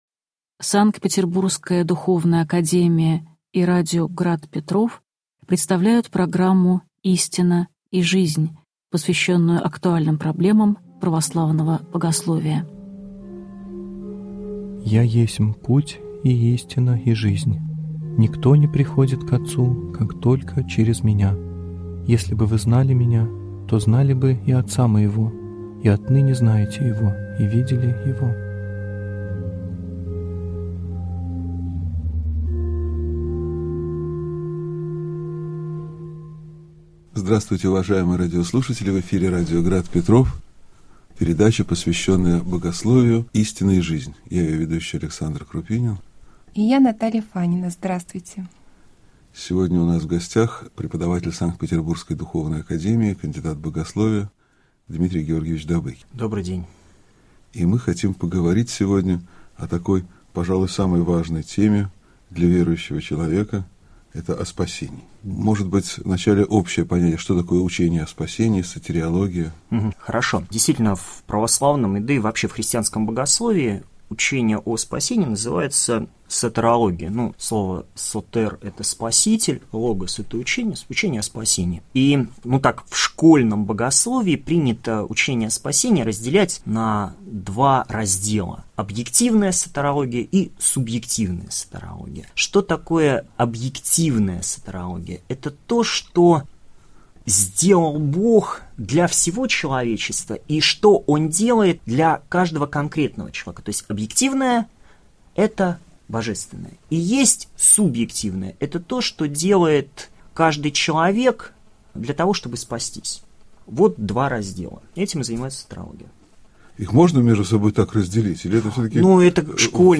Аудиокнига Учение о Спасении (часть 1) | Библиотека аудиокниг